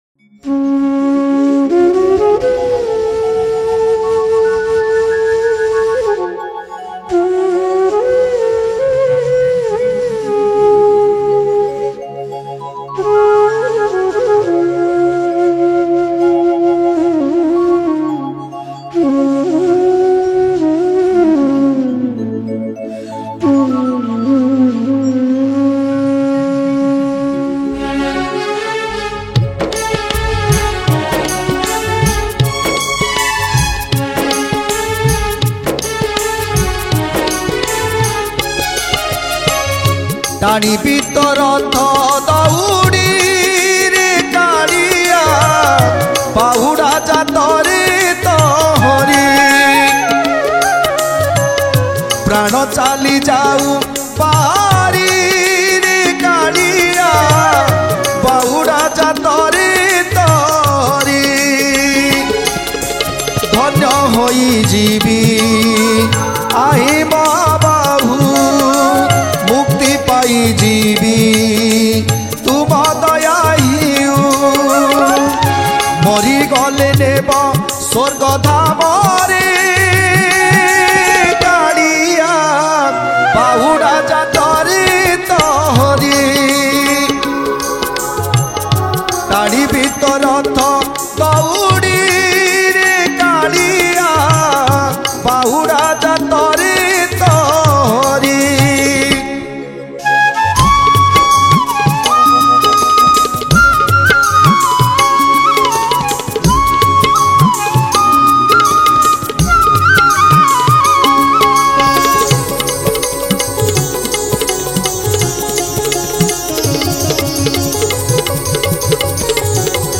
Ratha Yatra Odia Bhajan 2024 Duration